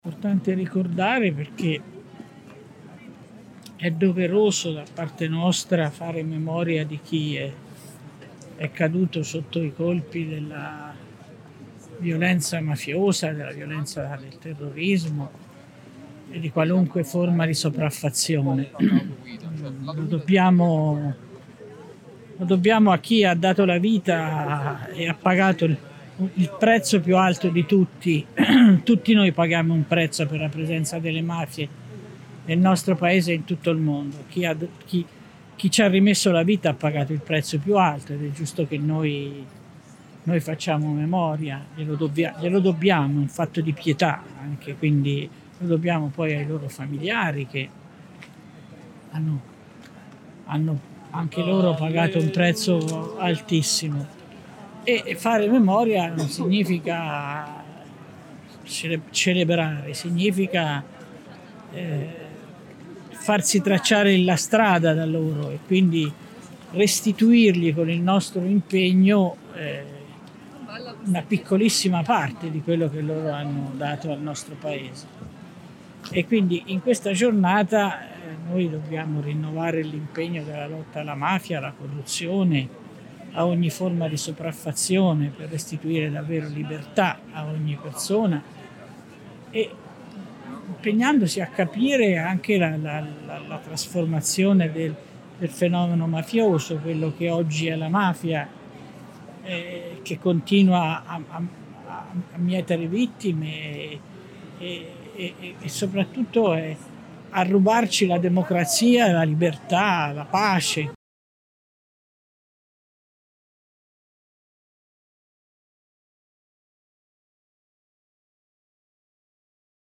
Abbiamo raccolto alcune voci e riflessioni provenienti dalla società civile, dalle associazioni, dalla politica
L’intervista a Rosy Bindi, politica, ex ministra, già presidente della commissione parlamentare antimafia